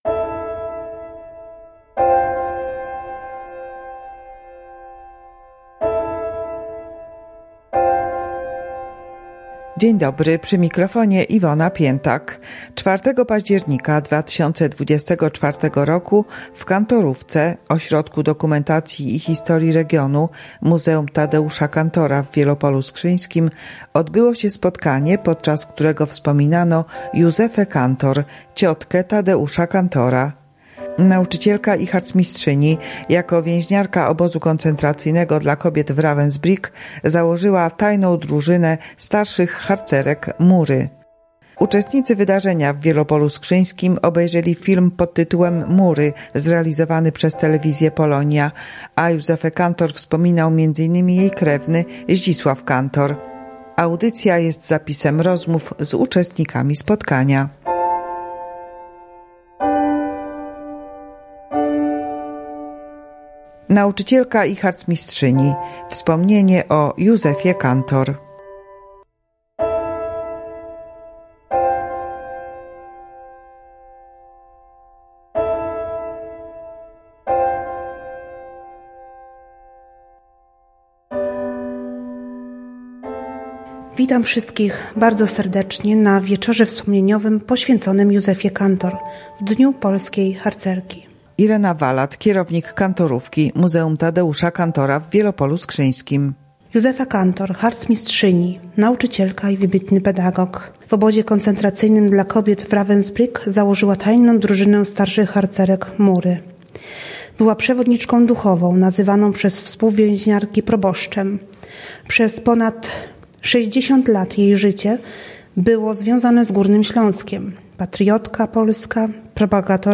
jest zapisem rozmów z uczestnikami wydarzenia.